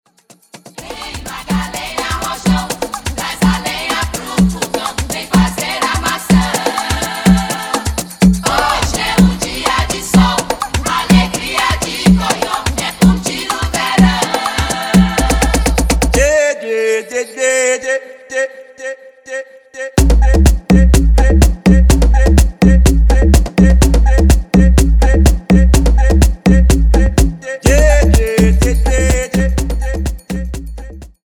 House
DJ